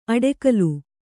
♪ aḍekalu